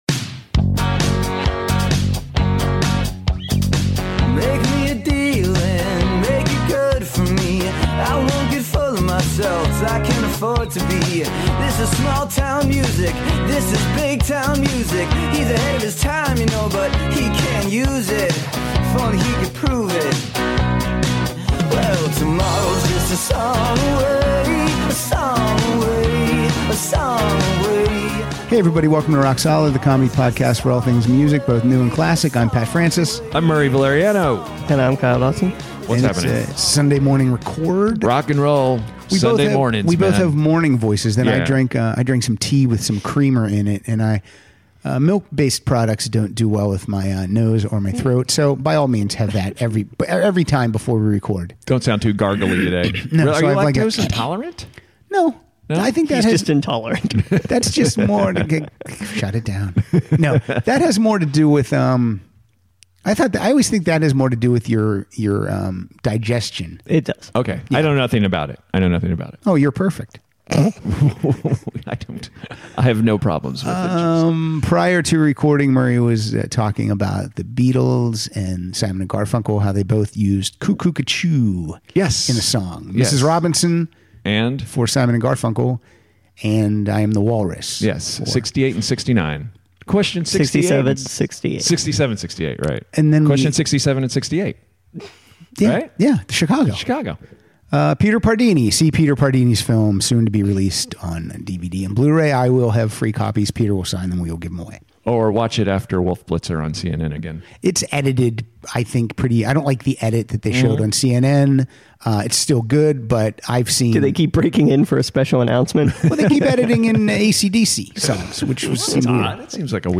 Don't worry people it's not all "grunge."